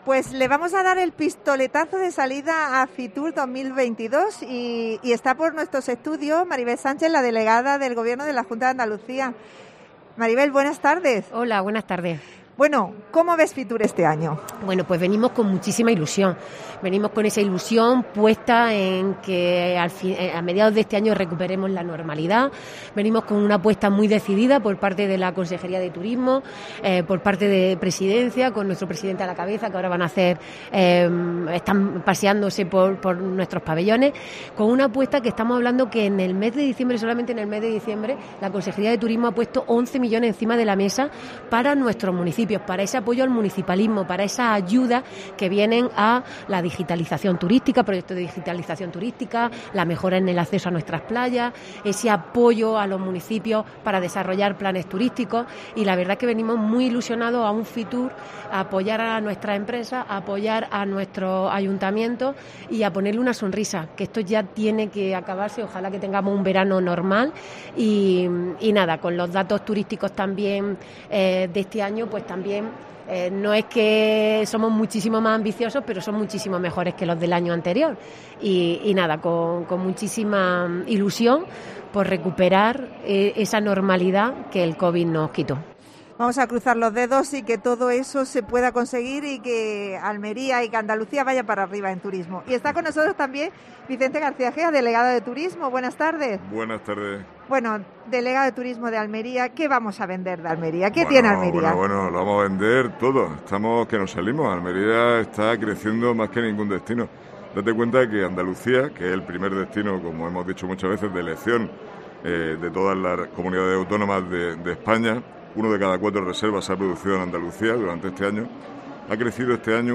Los programas especiales de COPE Almería desde FITUR han comenzado con la entrevista a la Junta de Andalucía.